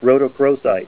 Help on Name Pronunciation: Name Pronunciation: Rhodochrosite + Pronunciation
Say RHODOCHROSITE Help on Synonym: Synonym: Dialogite   ICSD 100677   Manganese spar   PDF 44-1472   Parakutnohorite - intermediate composition between calcite and rhodochrosite